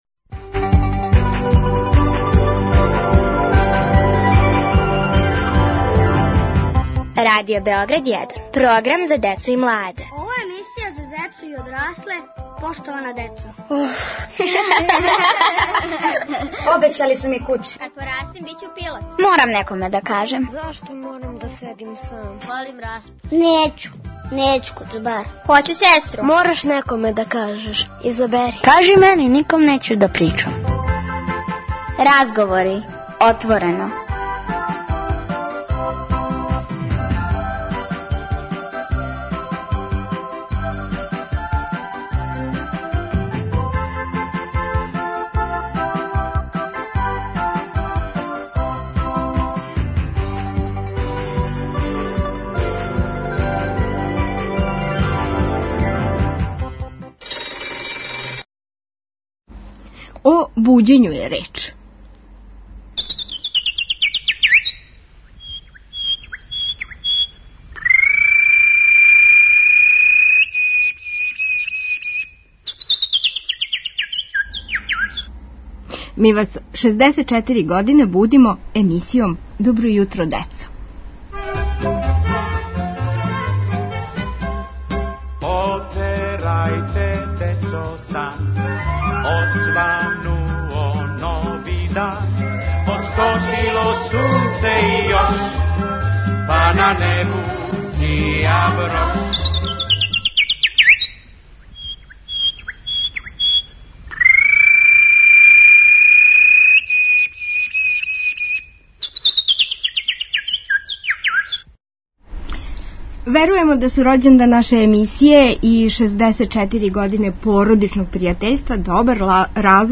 Инспирисана завршницом конкурса за кратку причу Добро јутро децо, поштована деца отворено разговарају о буђењу, устајању и разним јутарњим ритуалима којима она и њихове породице започињу дан.